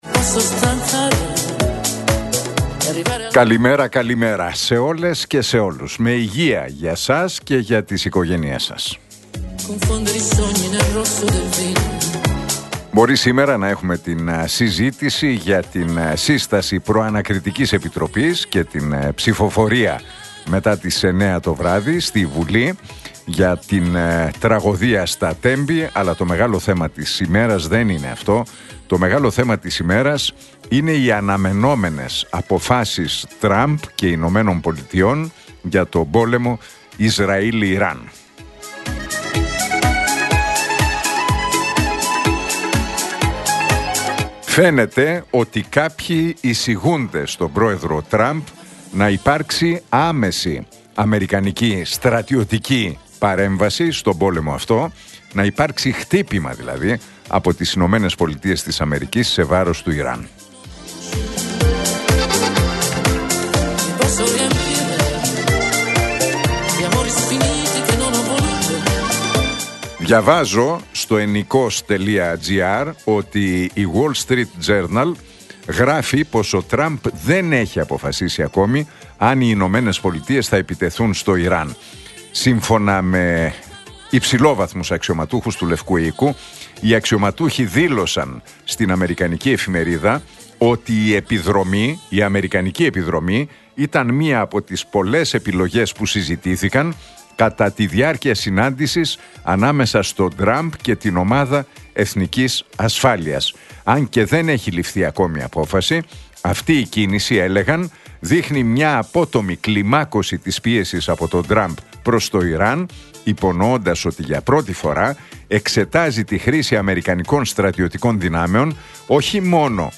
Ακούστε το σχόλιο του Νίκου Χατζηνικολάου στον ραδιοφωνικό σταθμό Realfm 97,8, την Τετάρτη 18 Ιουνίου 2025.